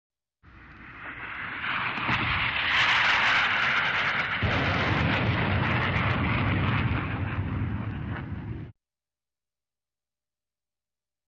Преодоление звукового барьера самолетом — звук
Преодоление звукового барьера самолетом слышится как хлопок.
Категория : Авиация Формат записей: mp3 Самолет F-15 преодолевает звуковой барьер Звуковой барьер
F-15-preodoleveaet-zvukovoj-barer.mp3